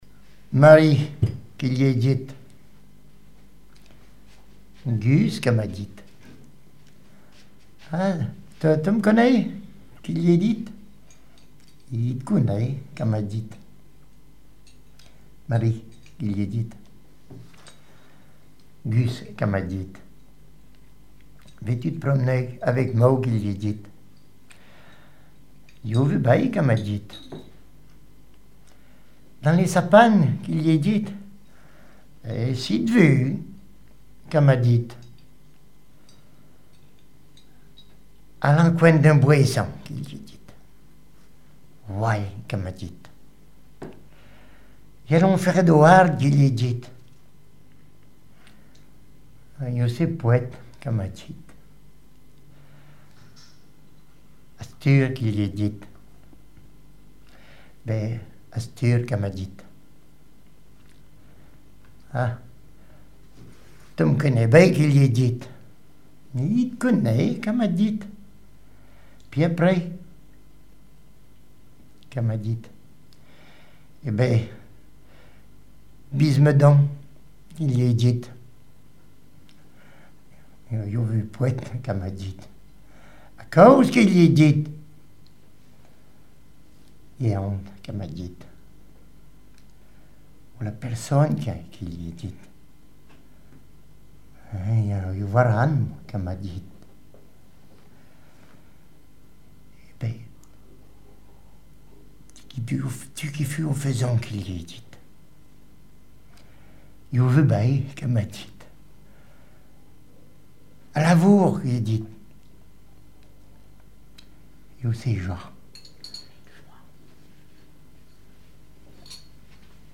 Langue Maraîchin
Genre sketch
Catégorie Récit